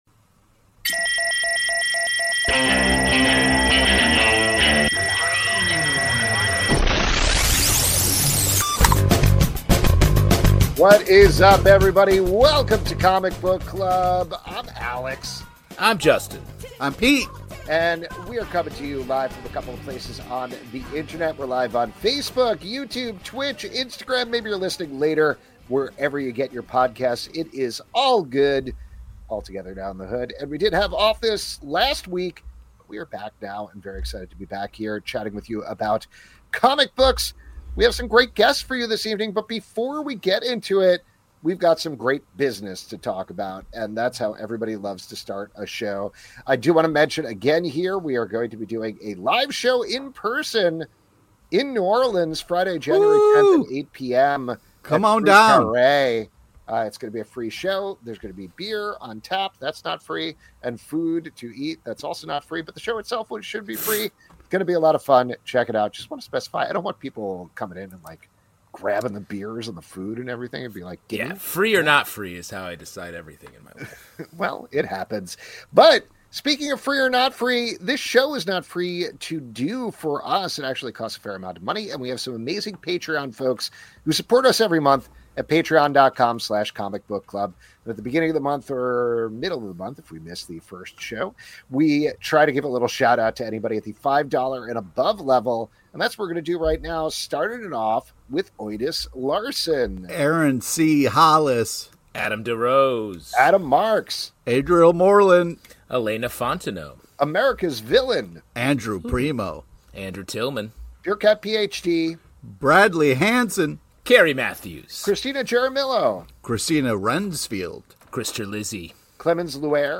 On this week's live show, we're welcoming guests